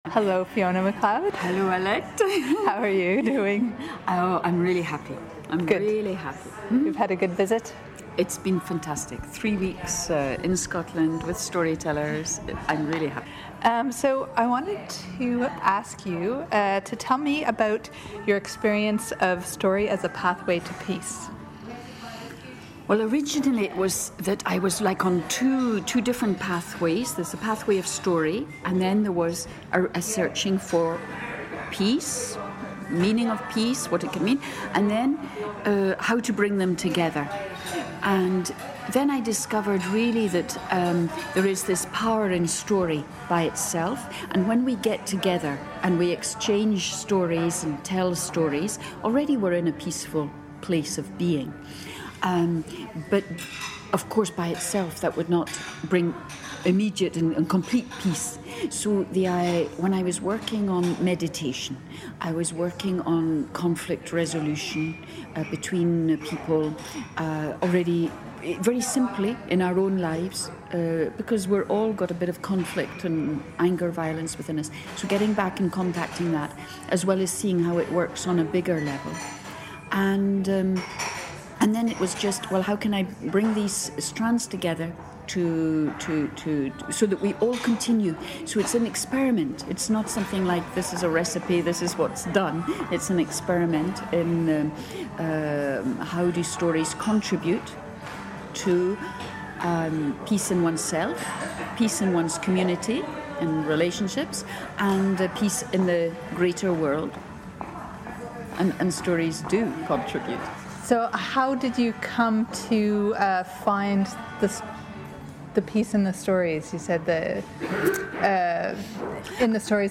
the first part of a two-part interview